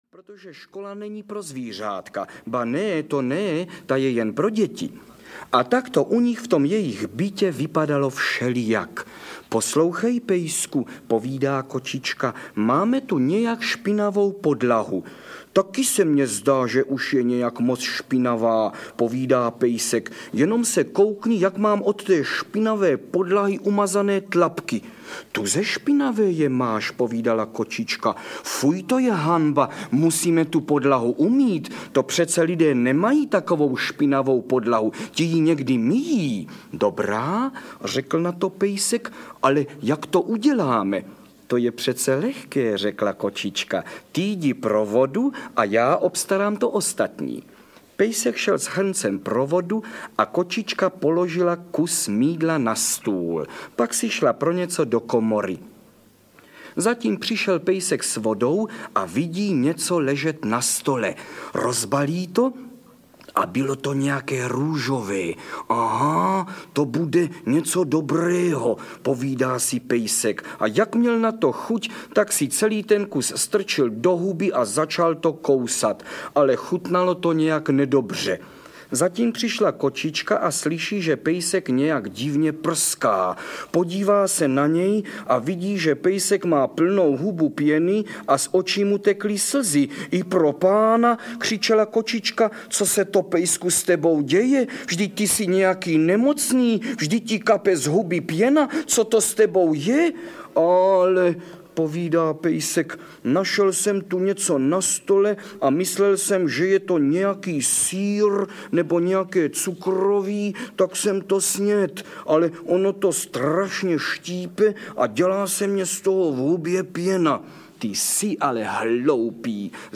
Karel Höger vypráví nejlepší pohádky audiokniha
Ukázka z knihy